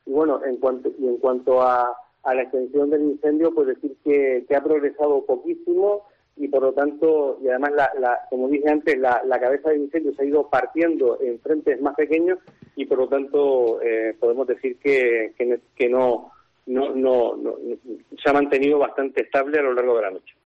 Raúl García Brink, consejero de Medio Ambiente de Gran Canaria, destaca la poca evolución del incendio